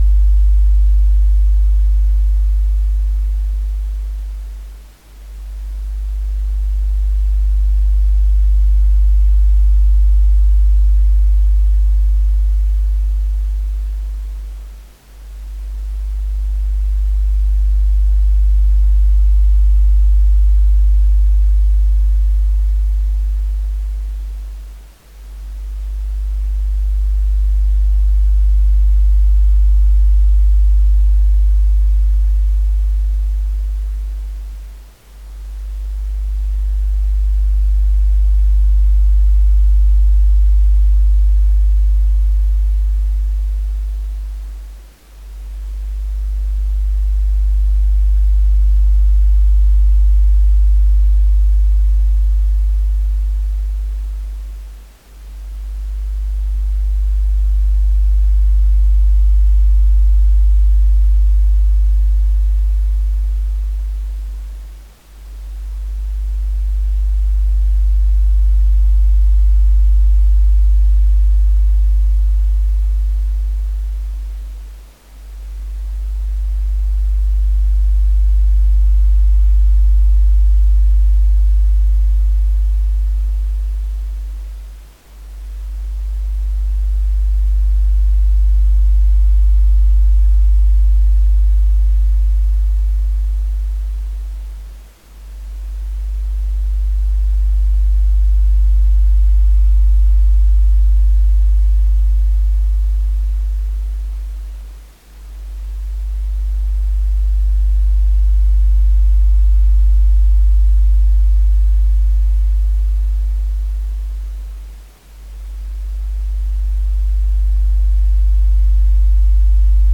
Sound of pink noise for newborn babies download and listen online
• Category: Pink Noise